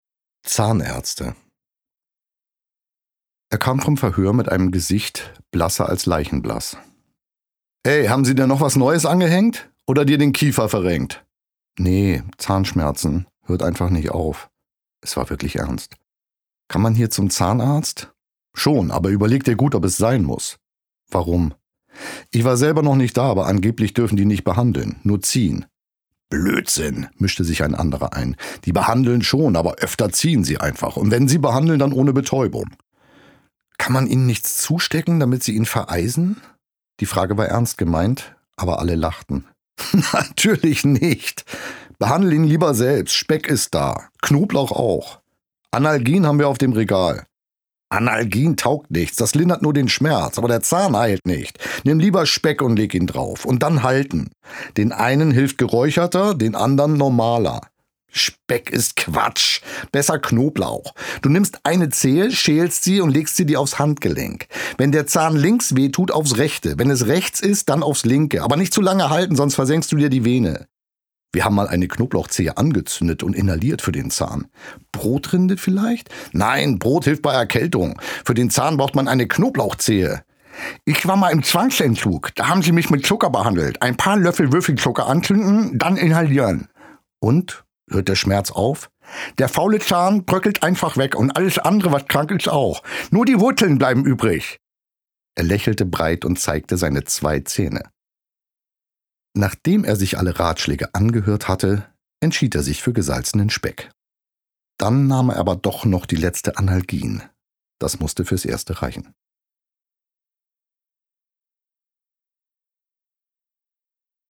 Bjarne Mädel (Sprecher)